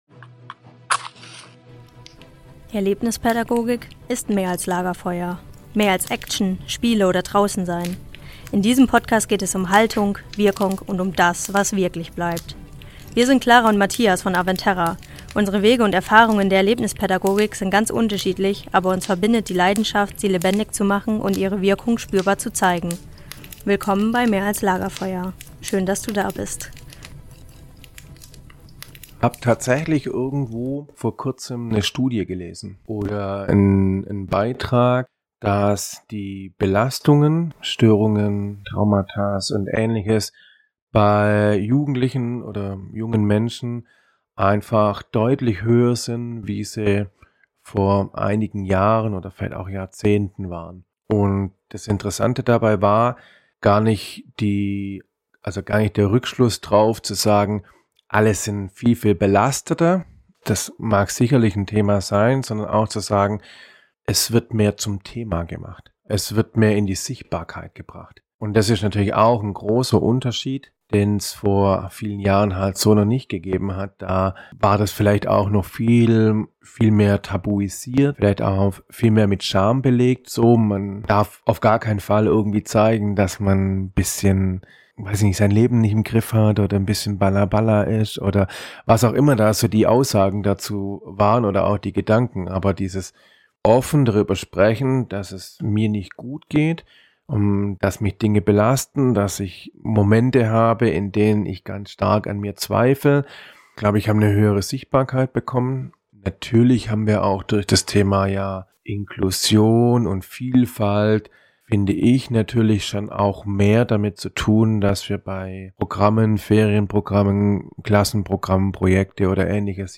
Hinweis zur Audioqualität: Das Audio hat diesmal leider ein paar kleine Macken. Es liegt also nicht an euren Lautsprechern .